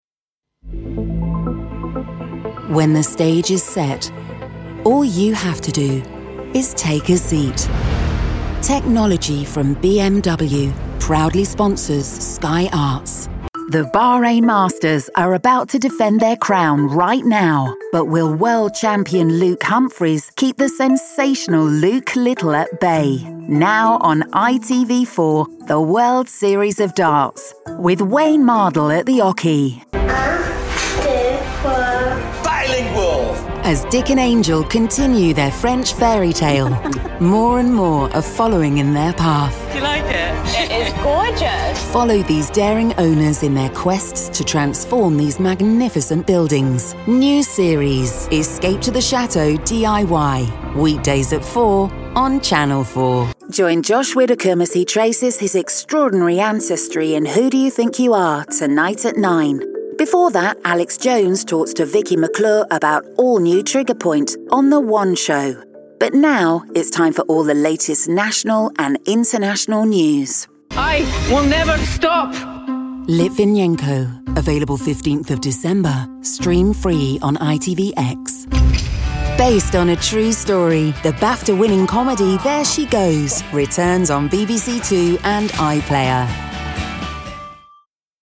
Multi-Nominated Professional British Voiceover. Clear, Contemporary, Confident.
TV Reel
Natural RP accent, can also voice Neutral/International and character.
Broadcast-ready home studio working with a Neumann TLM 103 mic.